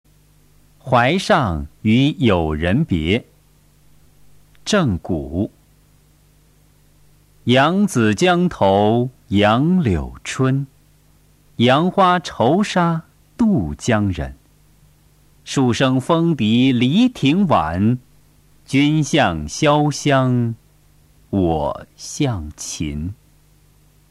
《淮上与友人别》原文和译文（含赏析、朗读）　/ 郑谷